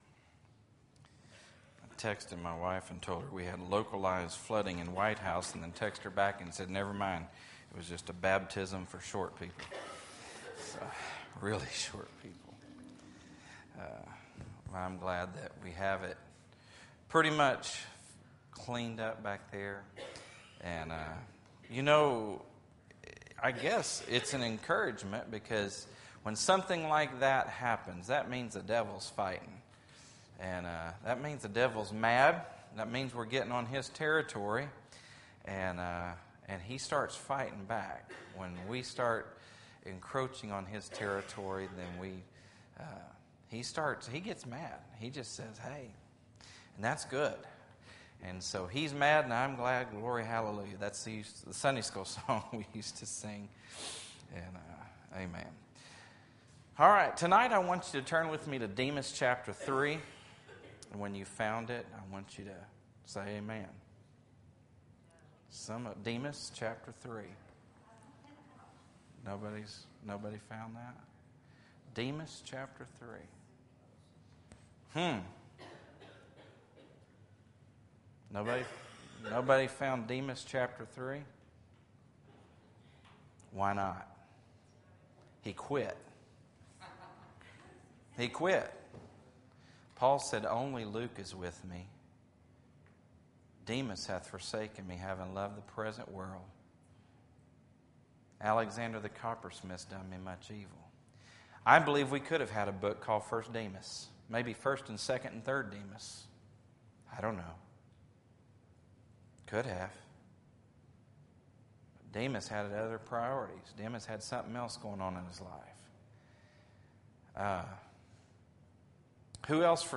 Fall Revival 2014